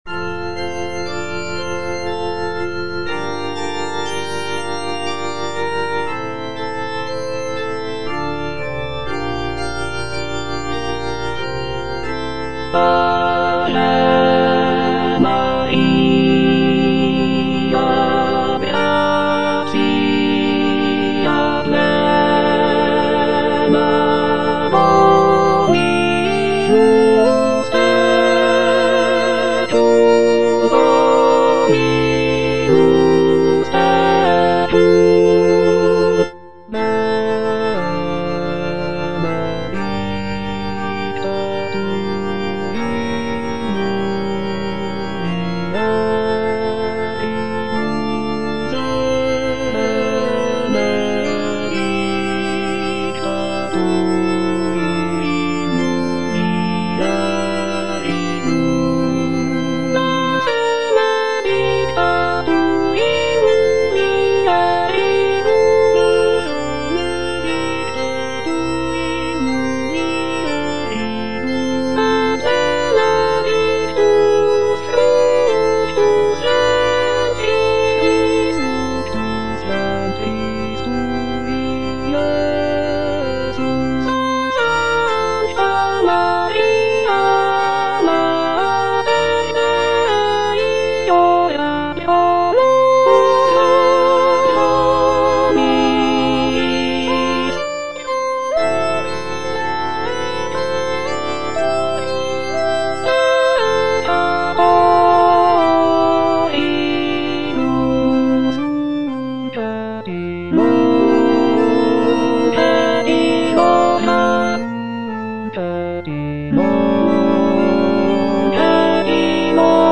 Alto (Emphasised voice and other voices)